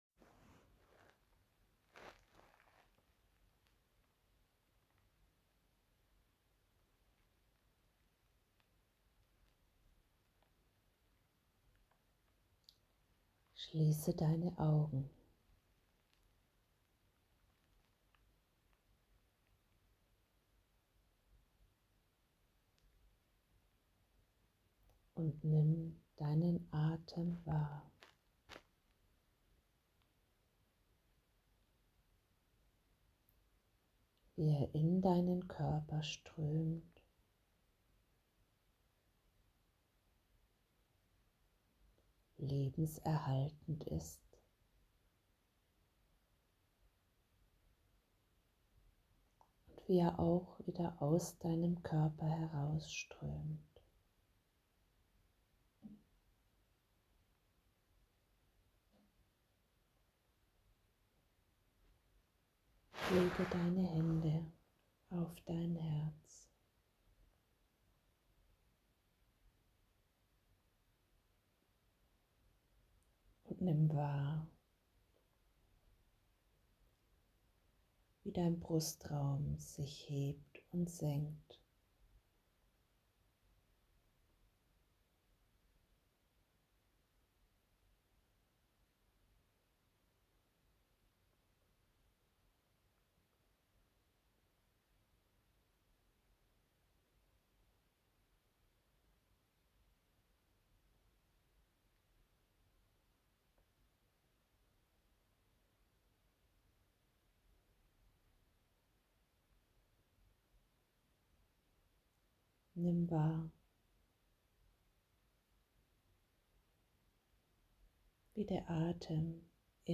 Meditation für Geborgenheit auf Spotify
Meditation_Geborgenheit1.mp3